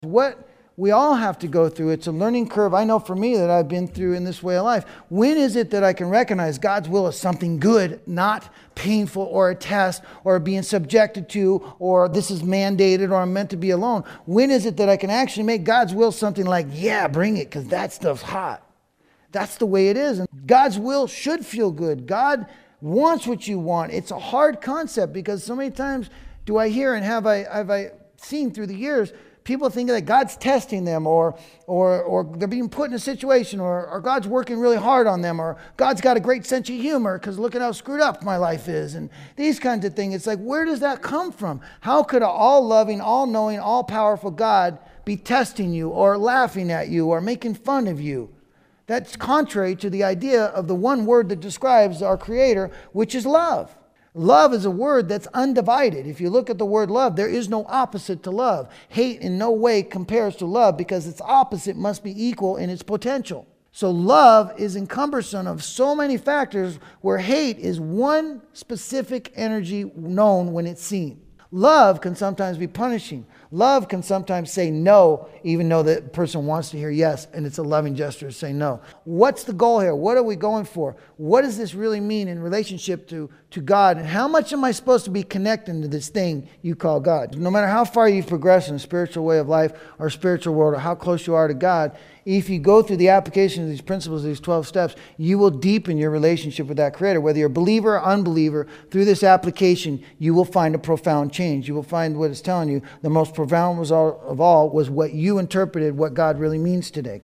This audio archive is a compilation of many years of lecturing.